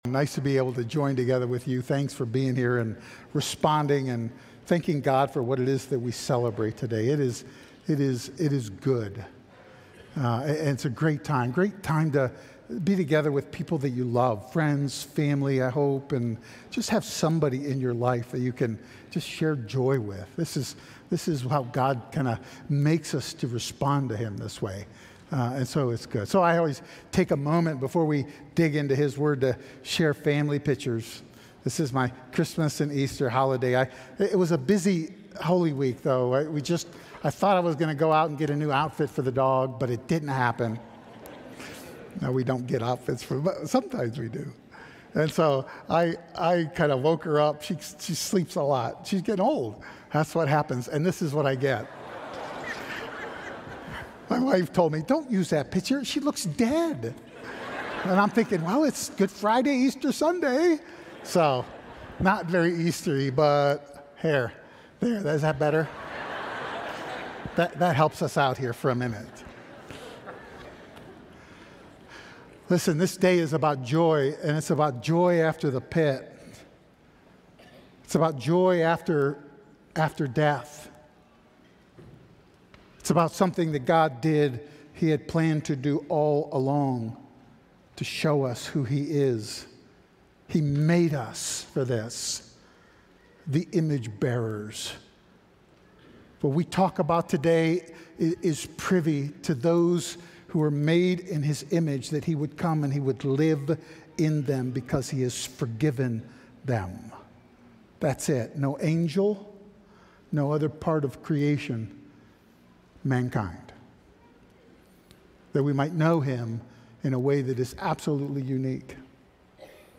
Romans 3:21, John 11:11-44 | The Power of God Audio File Sermon Notes More From This Series Farewell...